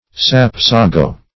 Sapsago \Sap"sa*go\, n. [G. schabzieger; schaben to shave, to